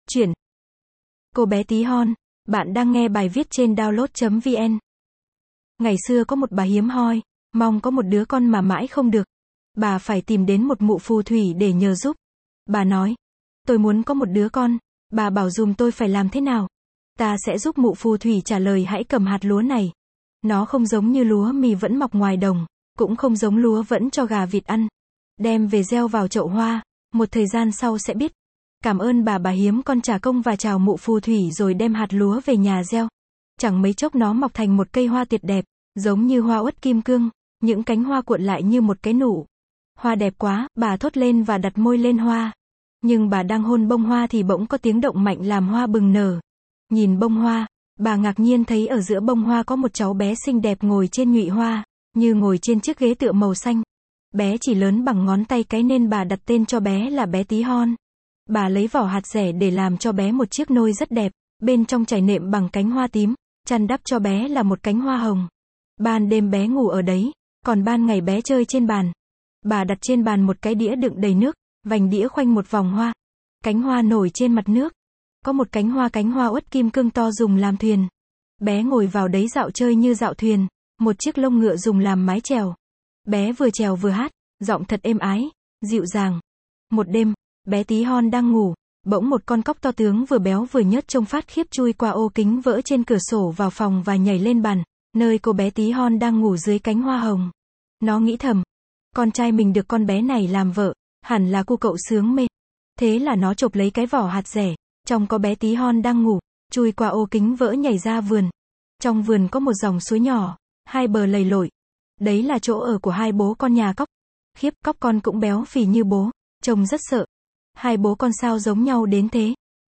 Sách nói | Cô bé tí hon